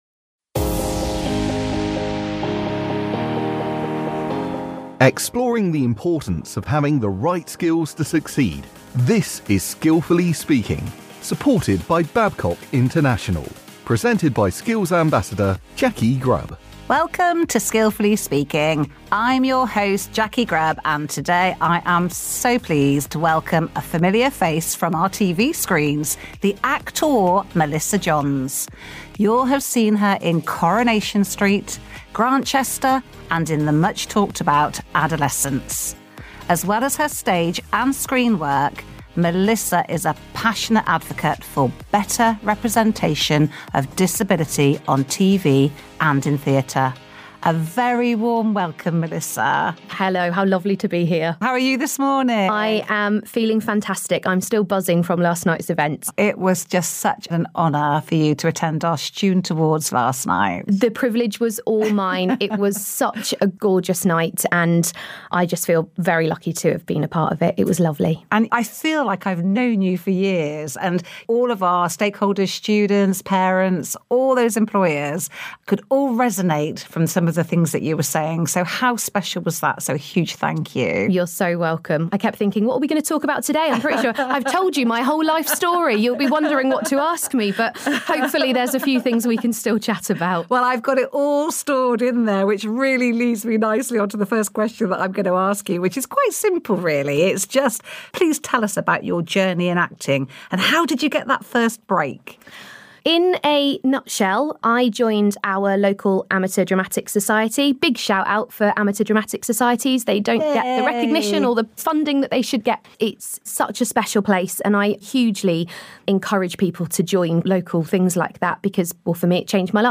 Melissa is a familiar face from the likes of Coronation Street, Grantchester and the much talked-about Adolescence. Recorded the morning after she presented the City College Plymouth student awards, Mellissa discusses the skills and strategy used to overcome negative attitude...